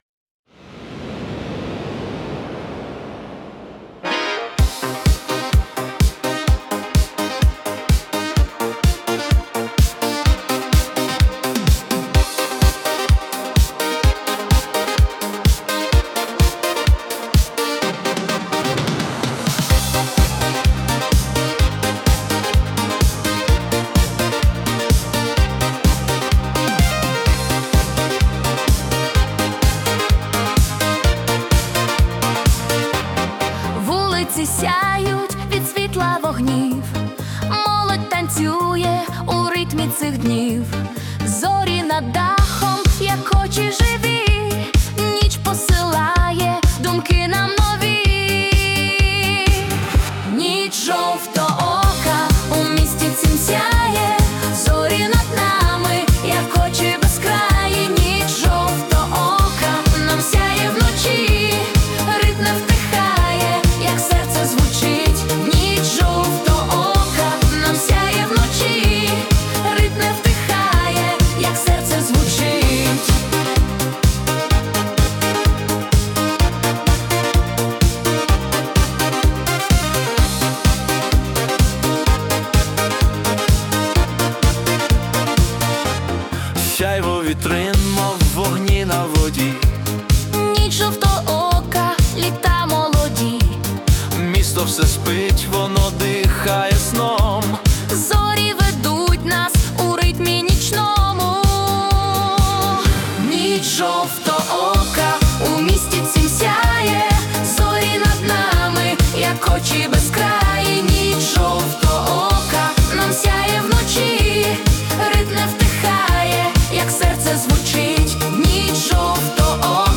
🎵 Жанр: City Lights Disco
енергійна та стильна композиція
у жанрі Italo Disco (120 BPM)